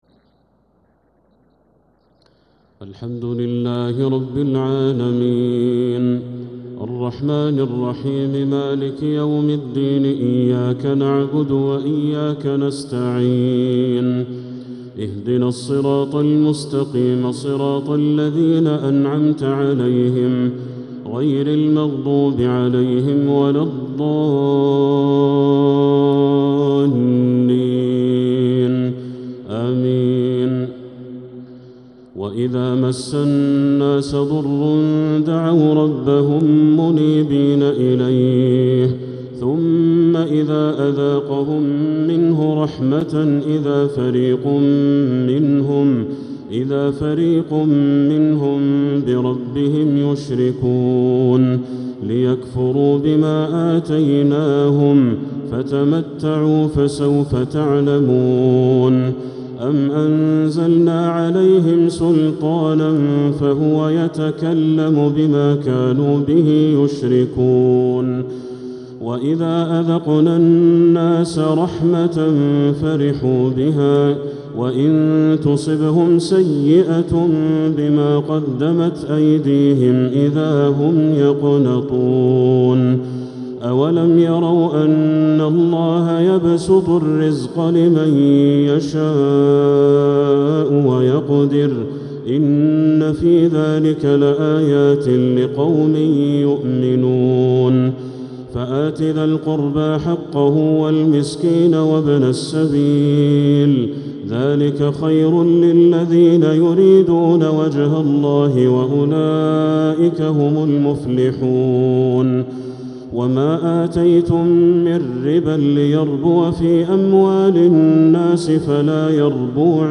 تراويح ليلة 24 رمضان 1446هـ من سورة الروم (33_60) إلى سورة السجدة كاملة | taraweeh 24th night Ramadan 1446H surah Ar-Rum to as-Sajdah > تراويح الحرم المكي عام 1446 🕋 > التراويح - تلاوات الحرمين